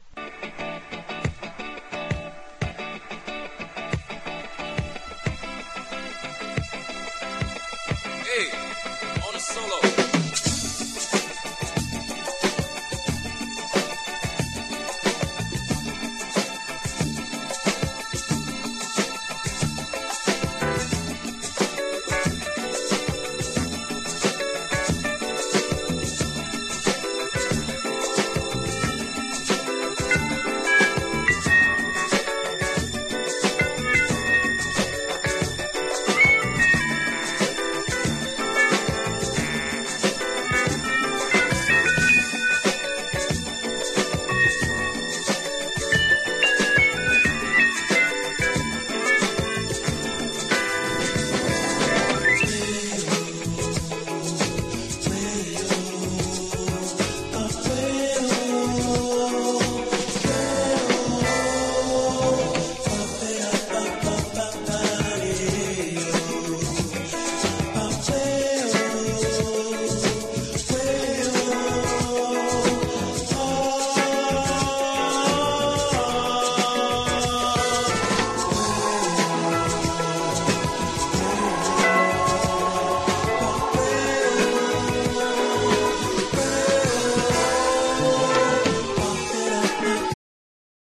ブレイク# RARE GROOVE# FREE SOUL